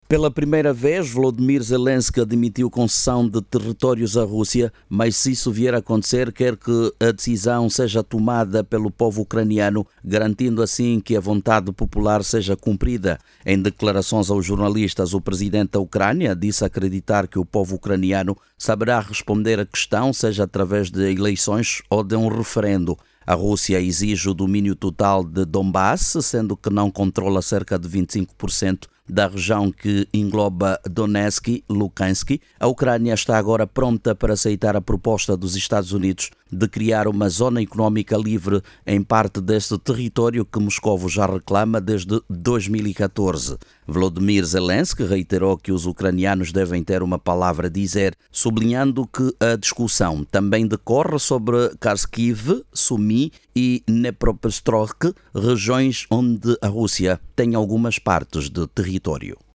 O Presidente ucraniano, Volodymyr Zelensky, afirmou na quinta-feira, 11 de Dezembro, que qualquer acordo entre a Ucrânia e a Rússia sobre o controlo do leste do país deve ser “justo” e validado através de eleições ou referendos realizados na Ucrânia. Jornalista